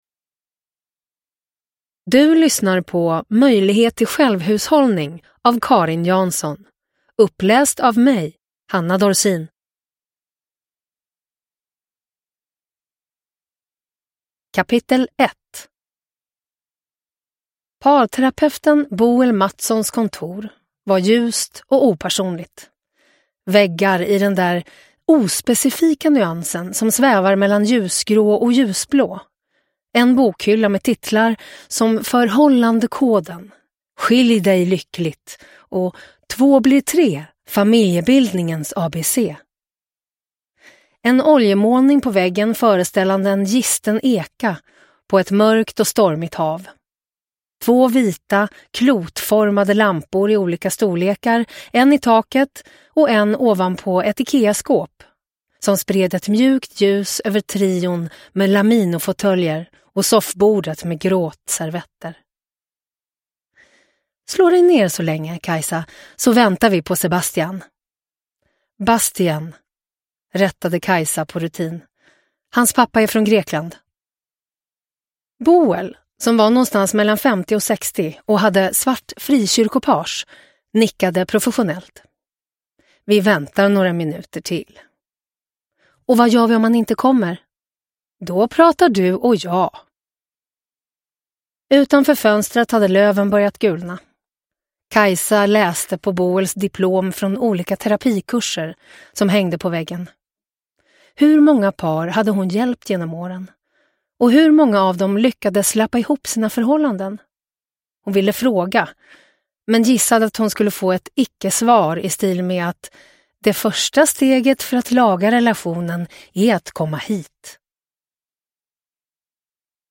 Möjlighet till självhushållning – Ljudbok – Laddas ner
Uppläsare: Hanna Dorsin